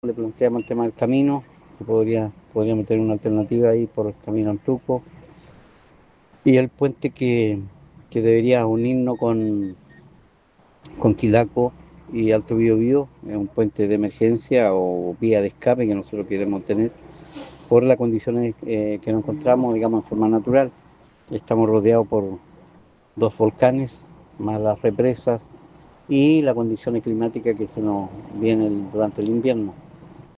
Finalmente, Félix Vita, alcalde de Alto Biobío, aprovechó la instancia para plantear otras urgencias de su comuna.